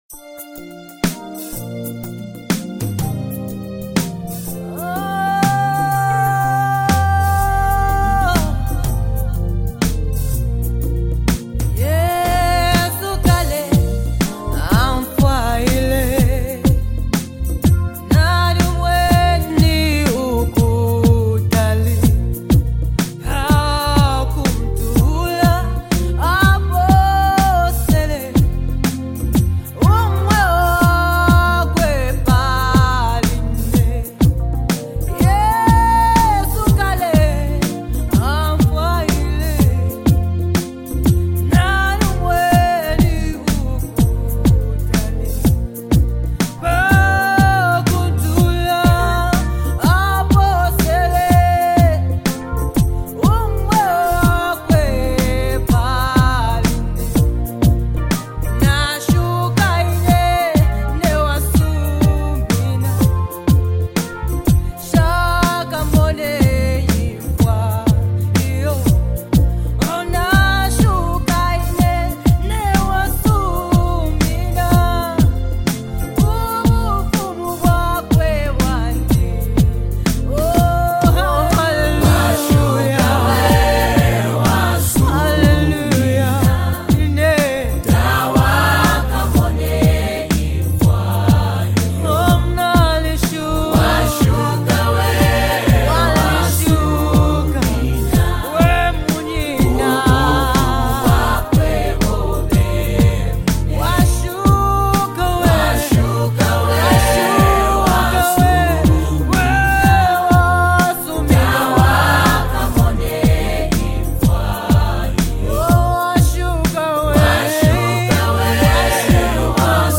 Gospel Music
vibrant African rhythms